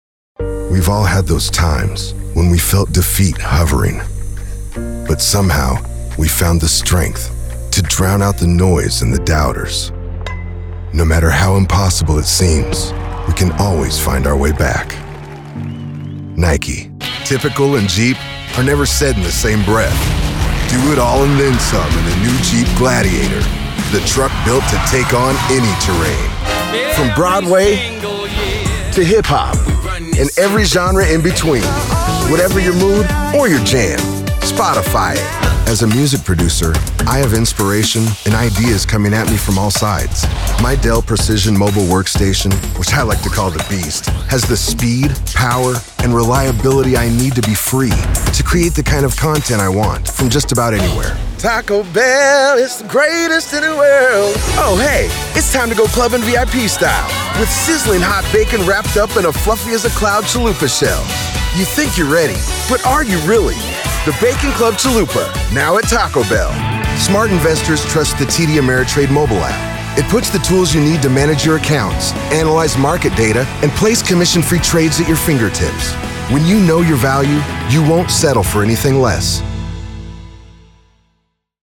Commercial Demo